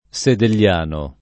[ S edel’l’ # no ]